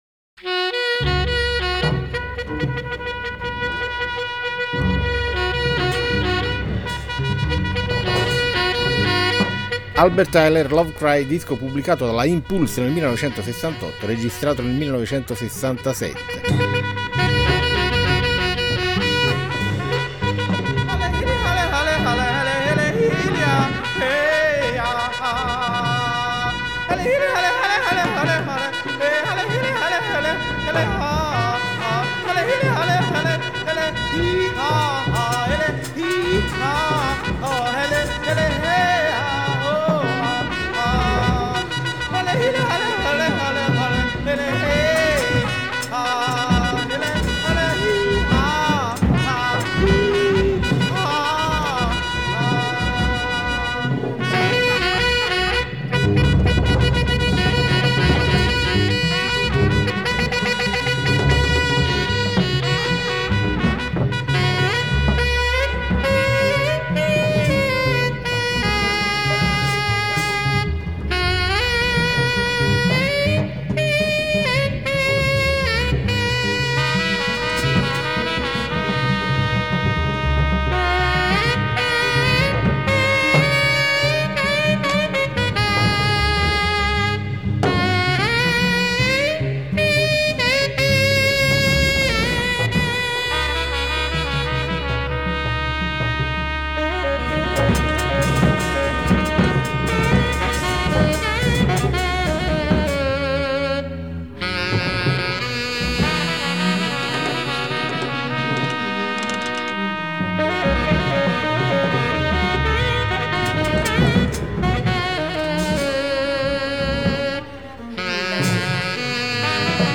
trumpet
tenor sax
piano
bass
drums